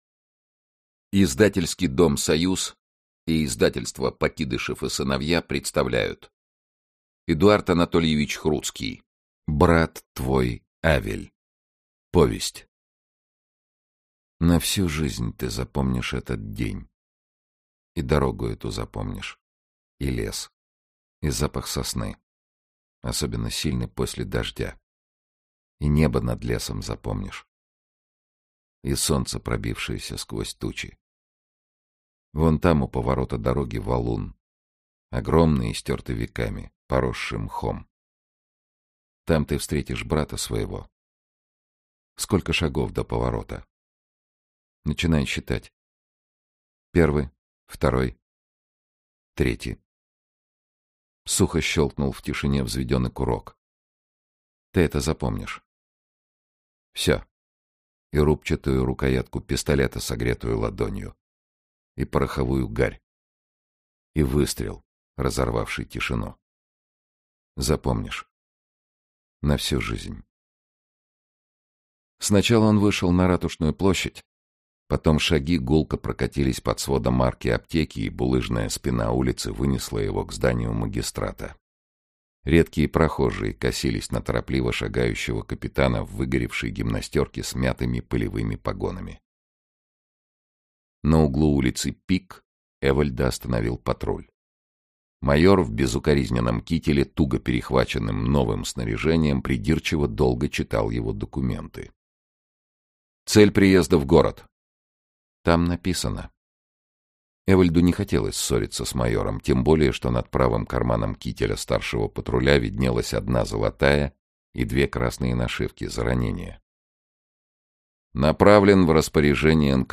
Aудиокнига Брат твой Авель Автор Эдуард Хруцкий Читает аудиокнигу Сергей Чонишвили.